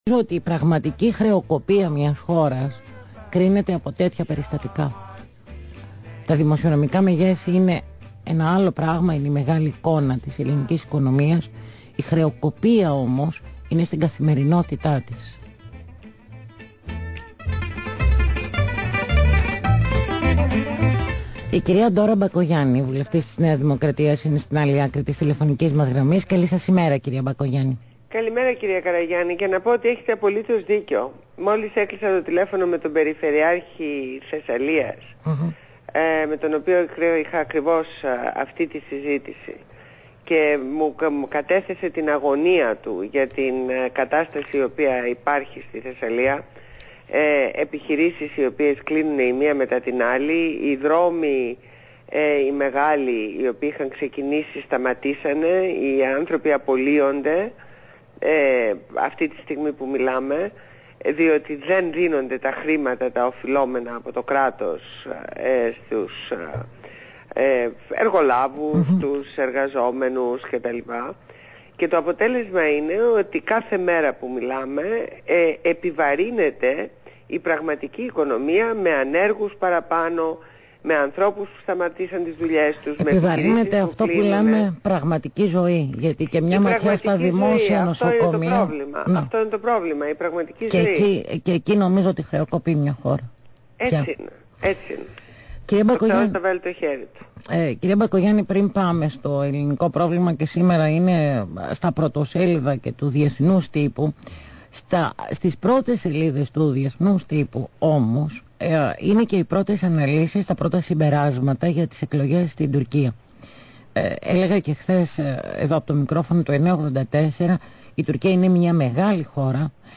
Συνέντευξη στο ραδιόφωνο Αθήνα 9,84fm